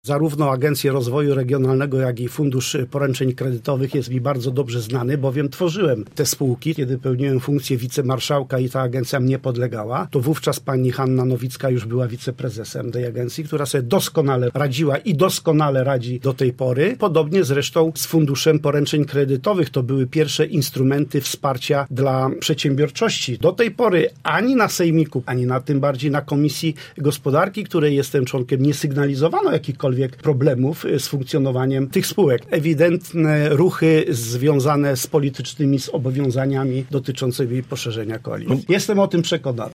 O zmianach na stanowiskach w lubuskich spółkach marszałkowskich mówił dziś na naszej antenie Edward Fedko.